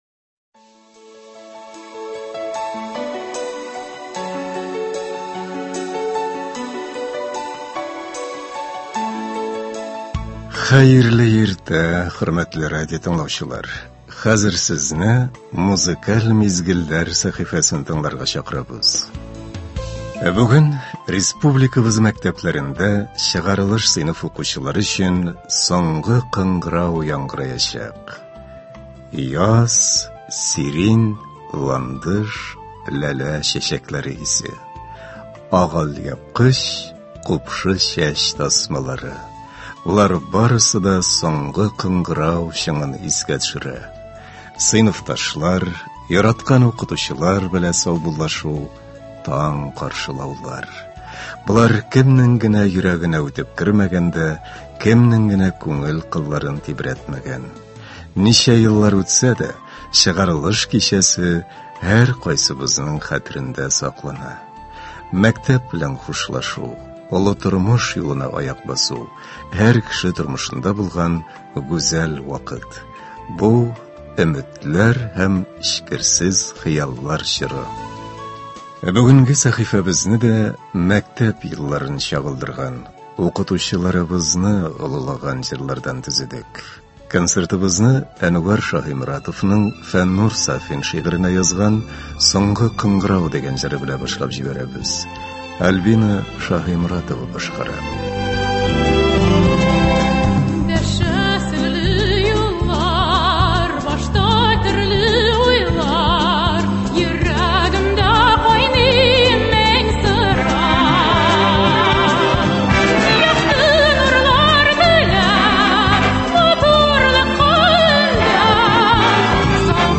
Музыкаль мизгелләр – бу иртәдә безнең радио сезгә көн буена яхшы кәеф бирә торган җырлар тәкъдим итә.